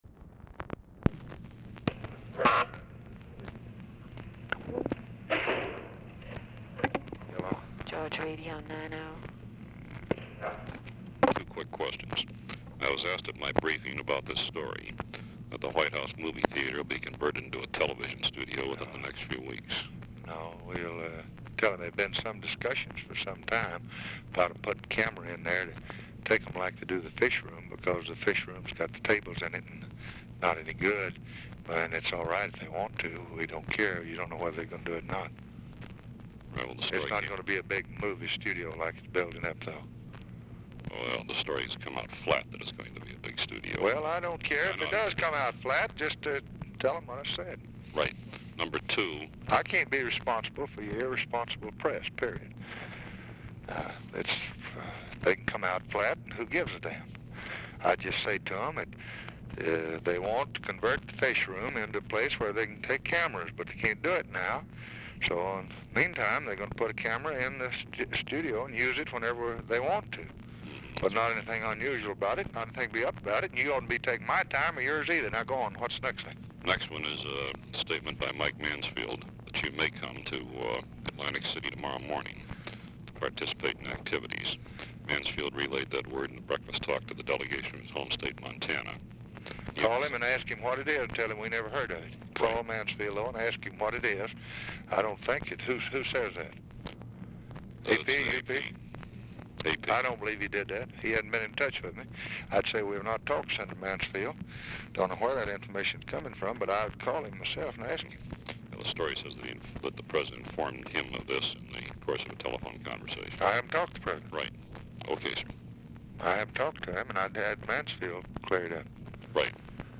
Telephone conversation # 5219, sound recording, LBJ and GEORGE REEDY, 8/26/1964, 12:41PM | Discover LBJ
Format Dictation belt
Location Of Speaker 1 Oval Office or unknown location
Specific Item Type Telephone conversation Subject Congressional Relations Elections National Politics Press Relations White House Administration